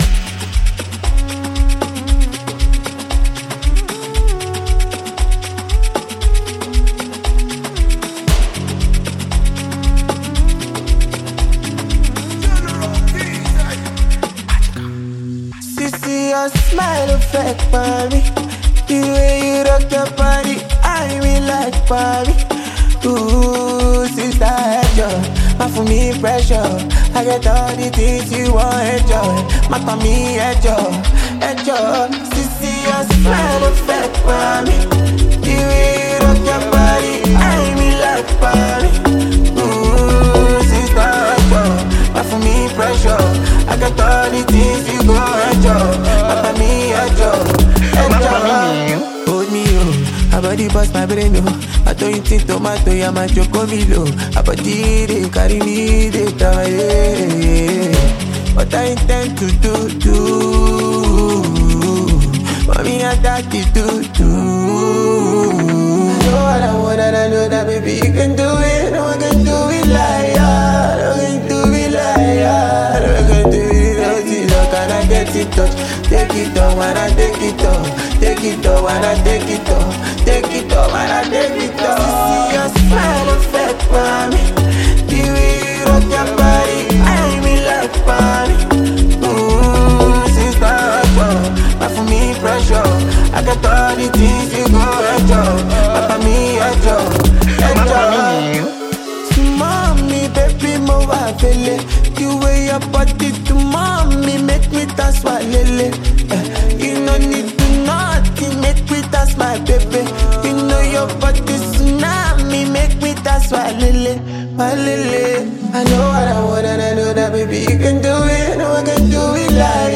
razor-sharp flows, bold lyrics, and a gritty street-hop beat
and anyone who craves music with attitude and rhythm.
riding a hard-hitting beat from the jump.